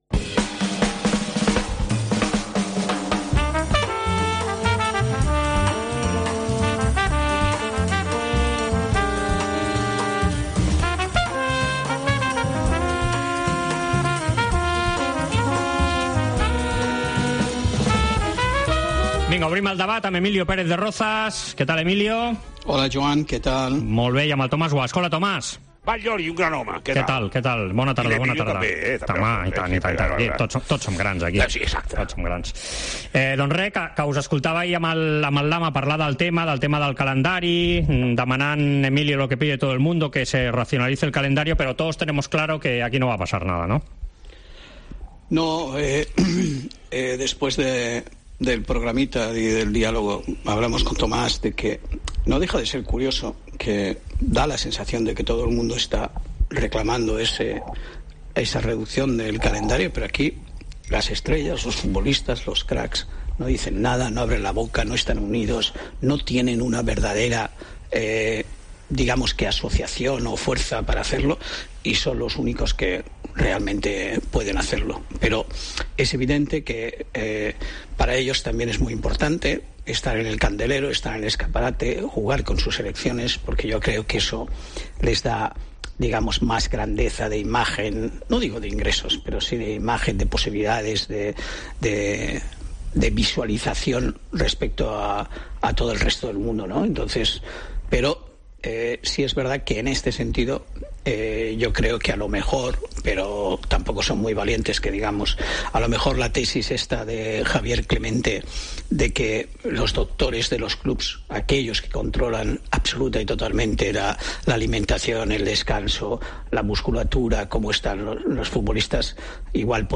AUDIO: Els dos col·laboradors de la Cadena COPE repassen l'actualitat esportiva de la setmana.